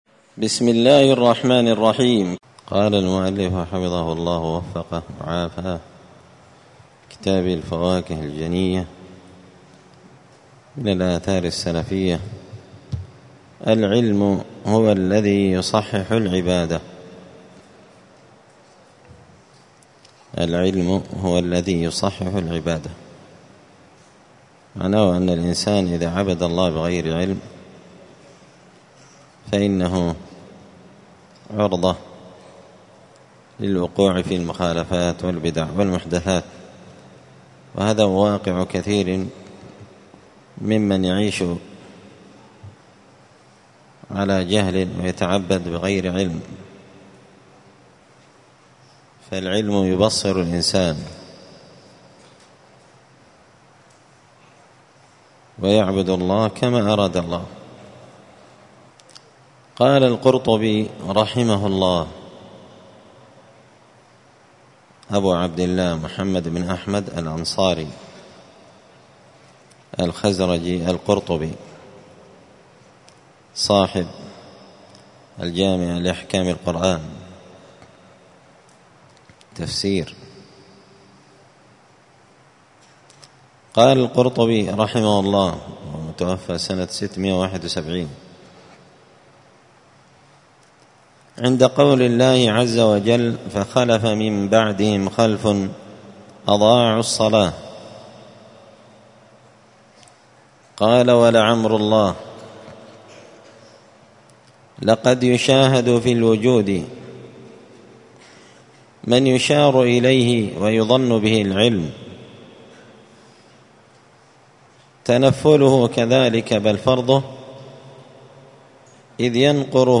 دار الحديث السلفية بمسجد الفرقان
7الدرس-السابع-من-كتاب-الفواكه-الجنية.mp3